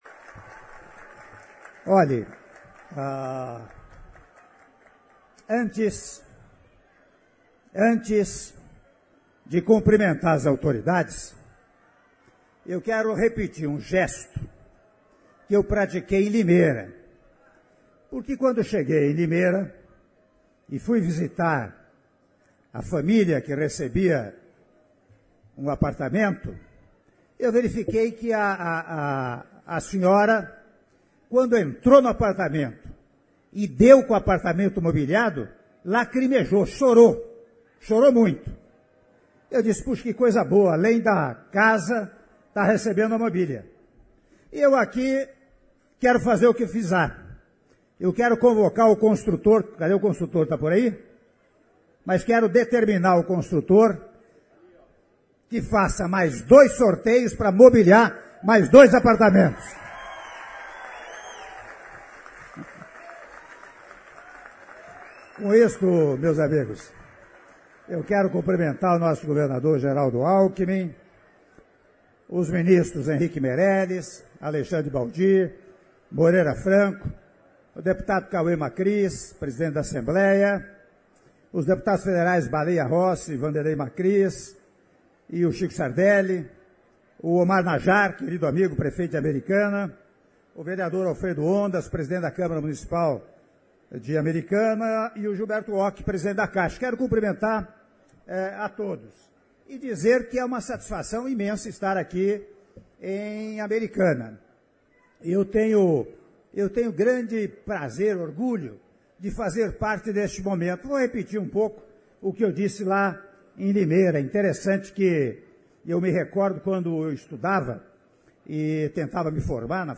Áudio do discurso do Presidente da República, Michel Temer, durante Cerimônia de Entrega de 896 UH do Condomínio Residencial Vida Nova I e II do Programa Minha Casa Minha Vida - (04min35s) - Americana/SP